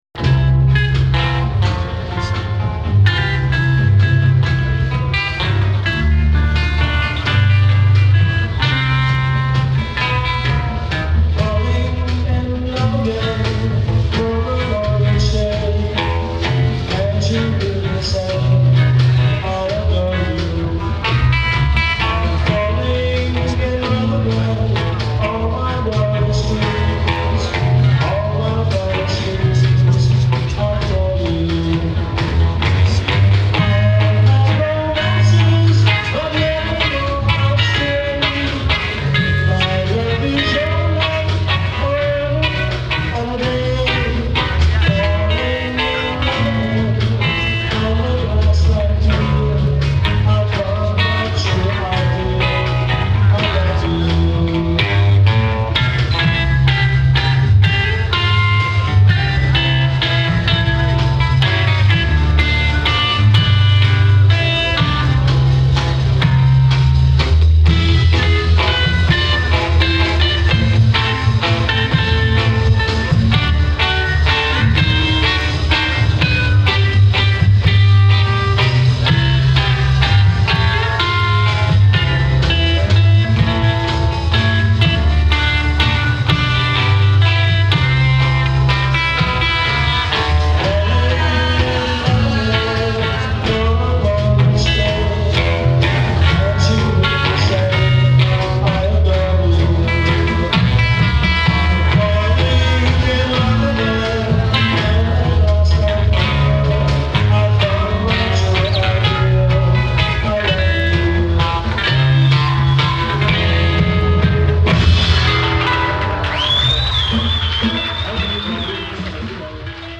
Группа обновила мелодию песни в стиле рок-н-ролла.